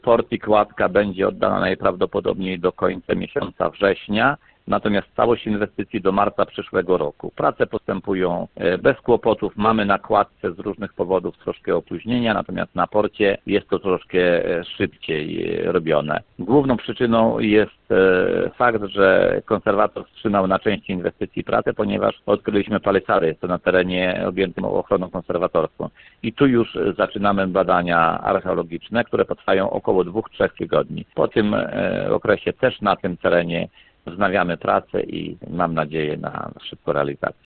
Modernizacja parków jest ściśle związana z budową portu, przebudową nabrzeży i budową kładki na rzece Pisie. – Ta inwestycja opiewa na kwotę 13 mln złotych – dodaje burmistrz Szymborski.